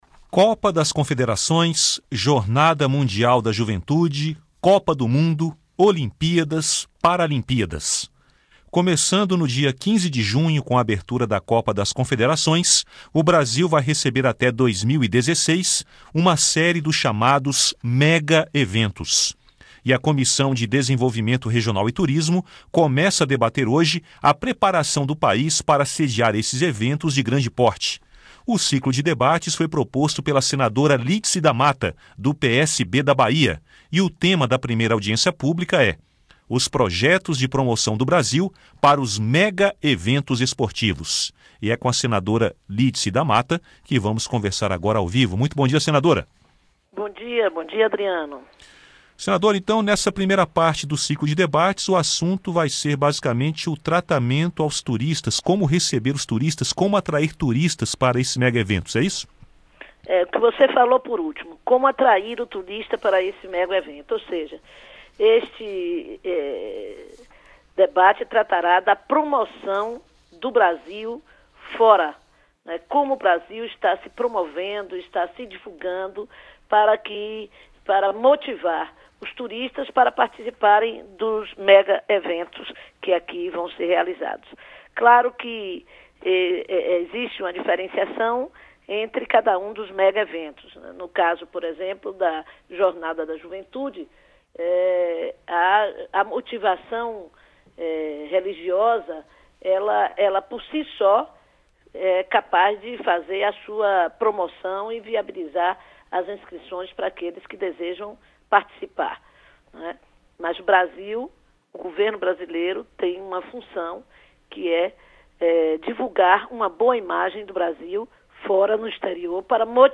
Entrevista com a senadora Lídice da Mata (PSB-BA).